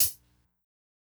Closed Hats
HIHAT_LATE.wav